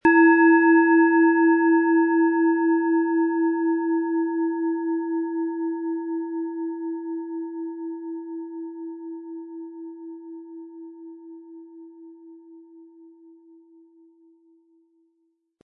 Tageston
Von erfahrenen Meisterhänden in Handarbeit getriebene Klangschale.
Wir haben versucht den Ton so authentisch wie machbar aufzunehmen, damit Sie gut wahrnehmen können, wie die Klangschale klingen wird.
Ein schöner Klöppel liegt gratis bei, er lässt die Klangschale harmonisch und angenehm ertönen.
MaterialBronze